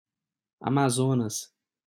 Amazonas[4] (Brazilian Portuguese: [ɐmaˈzonɐs]
Pt-br_Amazonas.ogg.mp3